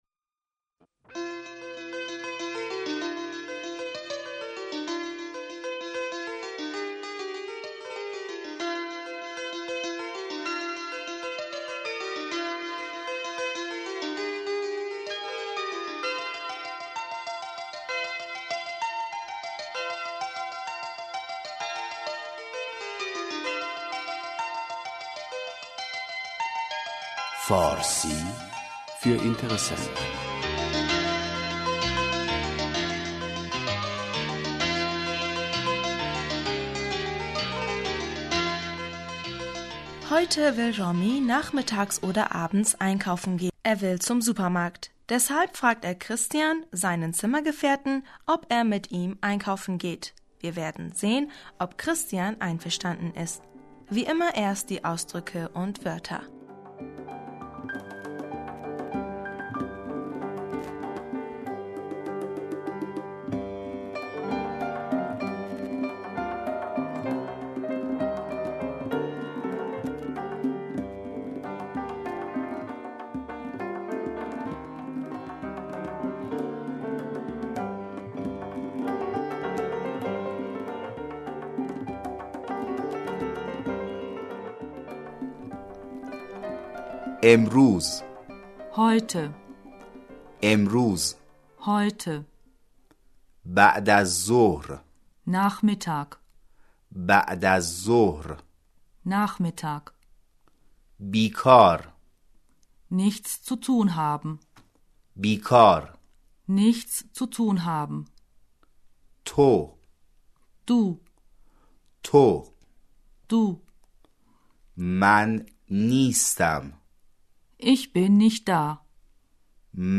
Plötzlich sagt Raamin: (Sir-o-Seda- Blättern im Buch) RAAMIN: Christian, hast du heute Nachmittag nichts zu tun? kristian, to emruz ba´d az zohr bikâri?